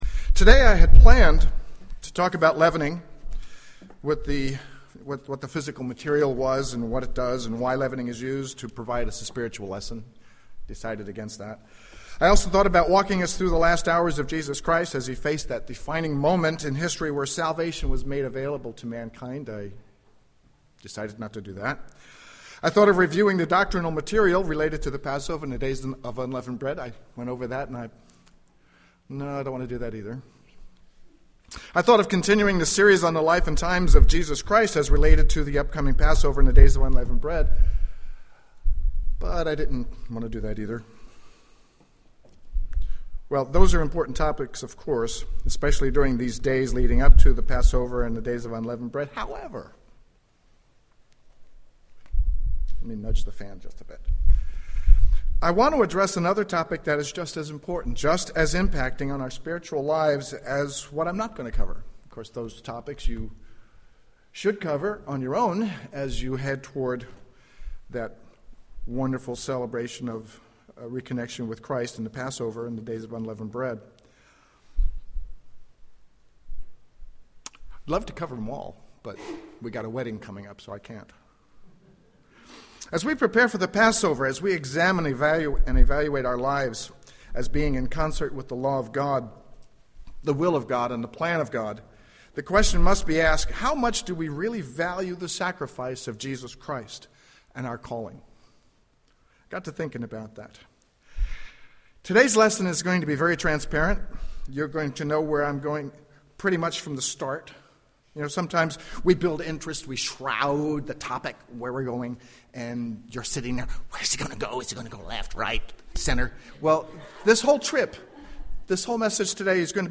Given in San Jose, CA
UCG Sermon Studying the bible?